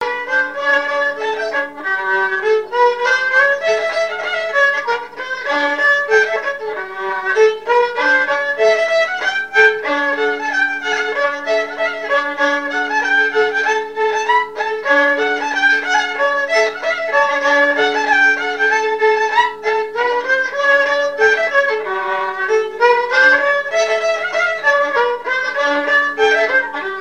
danse : scottich trois pas
Pièce musicale inédite